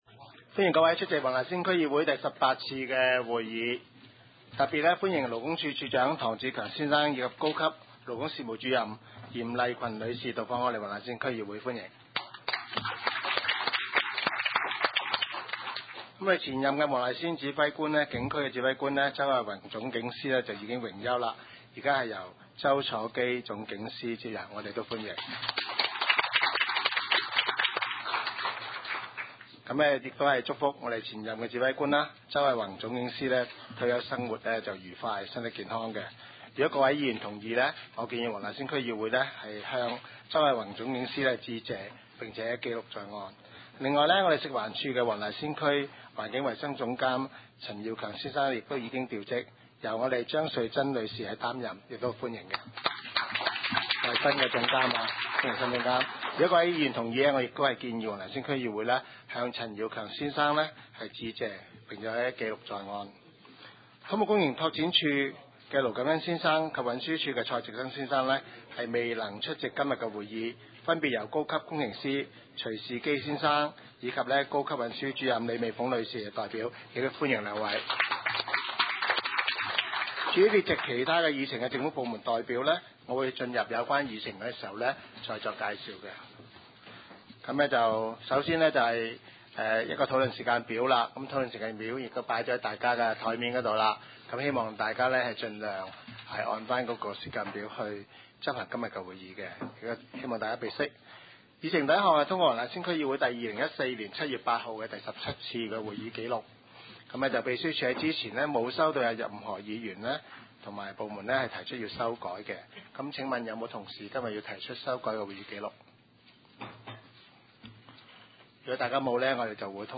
区议会大会的录音记录
黄大仙区议会会议室